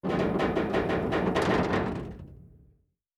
Metal Foley Creak 2.wav